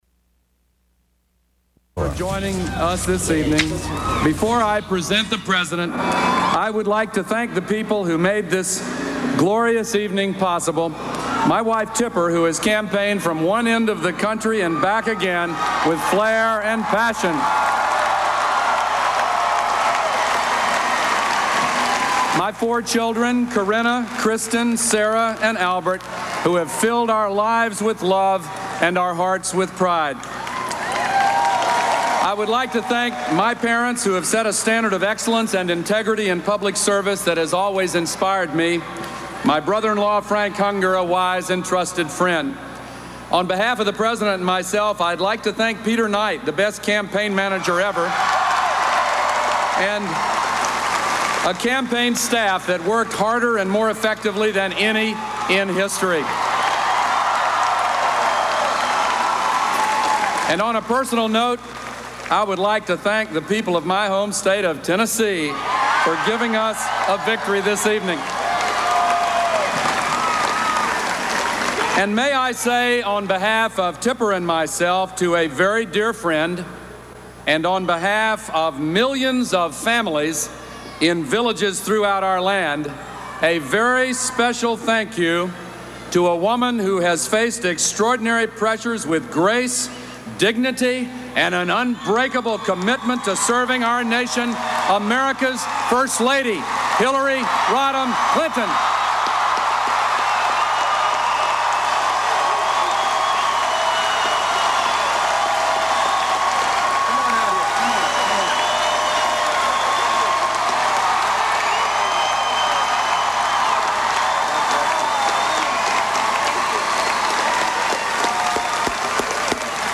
U.S. President Bill Clinton celebrates his re-election victory before a hometown crowd in Little Rock, AK